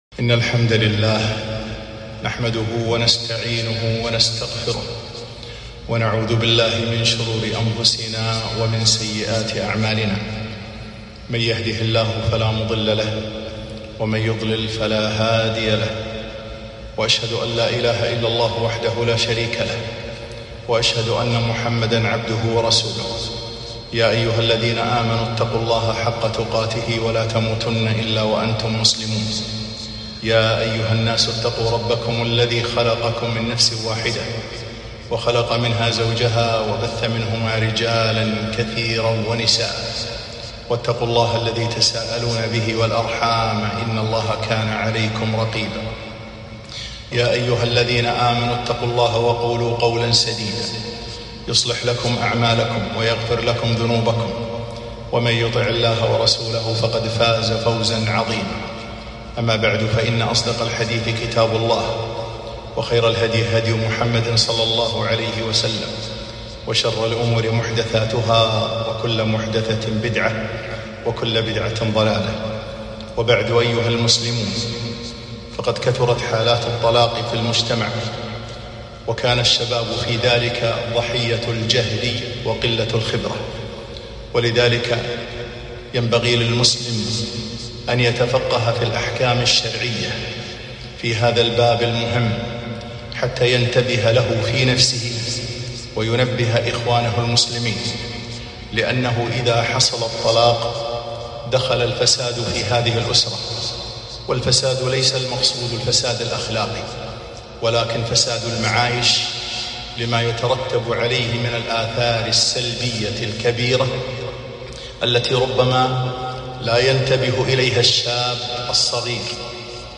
خطبة - قواعد مهمة في الطلاق